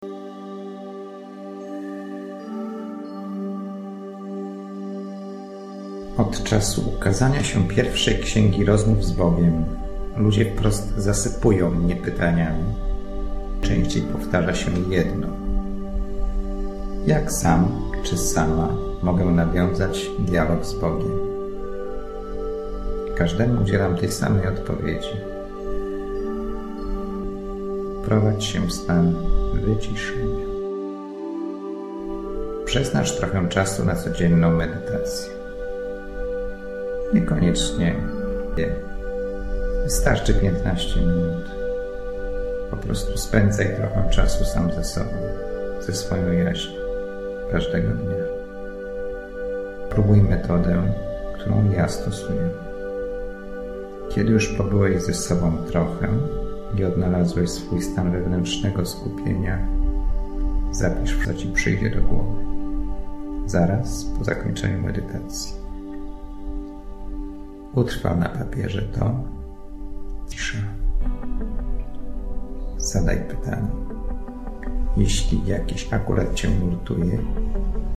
Wspania�a muzyka i doskonale dobrane Afirmacje z Drugiej Ksi�gi Medytacji do Rozm�w z Bogiem to oko�o 75 minutowy relaks podzielony na 12 miesi�cy w roku!
Muzyka zag�usza g�os lektora . Muzyka jednostajna i m�cz�ca. Odradzam zakupu w/w p�yty.